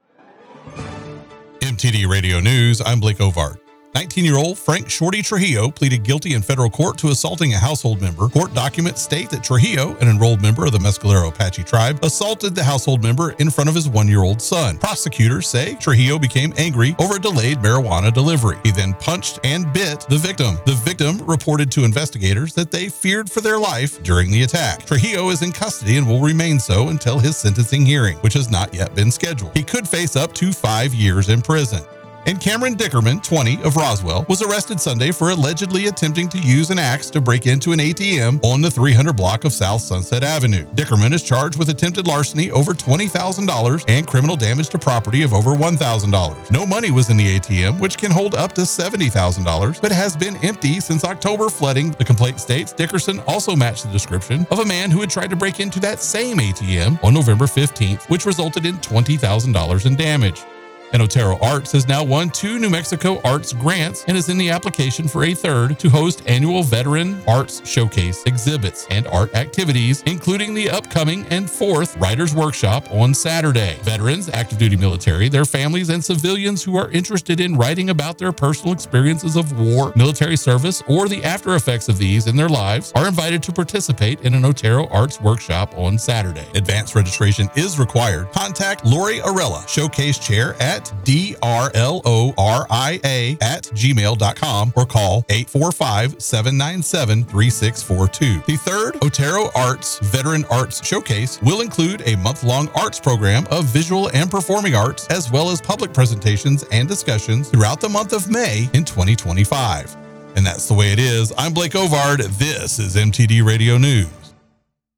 Mix 96.7 NEWS RUIDOSO AND LINCOLN COUNTY